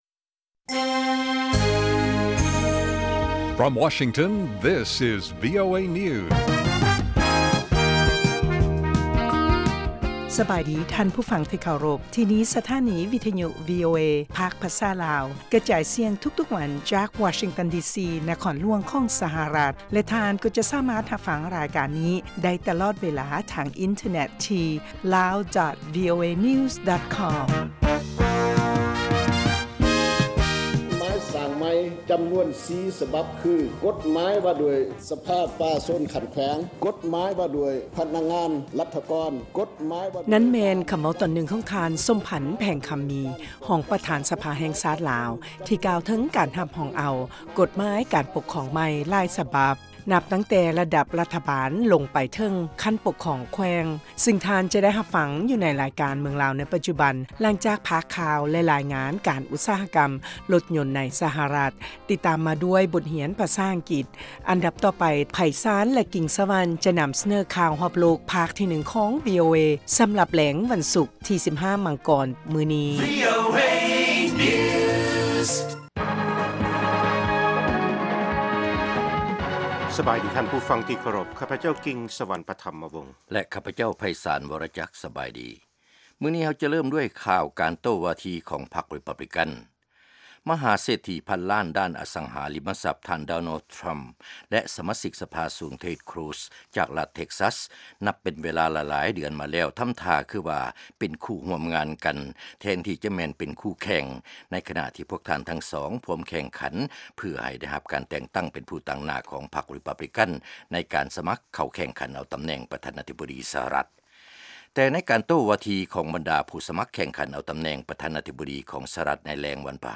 ລາຍການກະຈາຍສຽງຂອງວີໂອເອ ລາວ